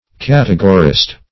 Search Result for " categorist" : The Collaborative International Dictionary of English v.0.48: Categorist \Cat"e*go*rist\, n. One who inserts in a category or list; one who classifies.
categorist.mp3